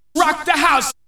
VOX SHORTS-2 0001.wav